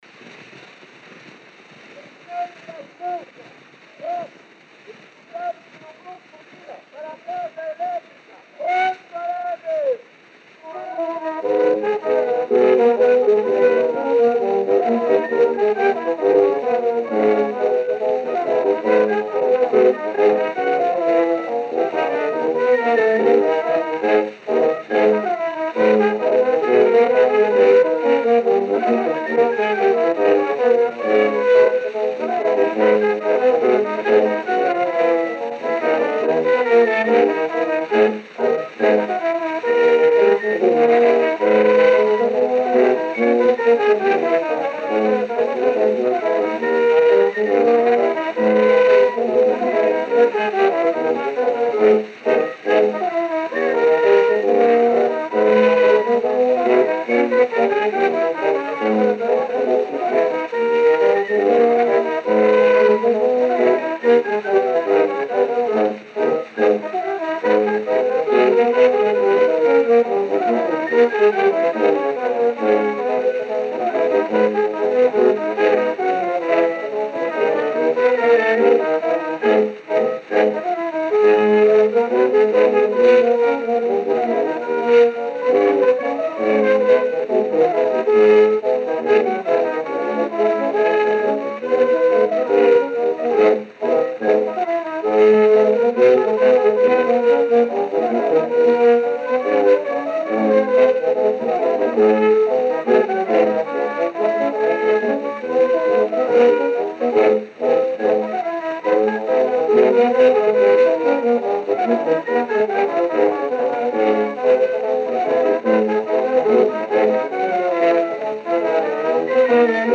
Disco de 78 rotações, também chamado "78 rpm", gravado em apenas um dos lados com rótulo tricolor.
A performance da gravação foi executada pelo Grupo.
Gênero: Schottisch.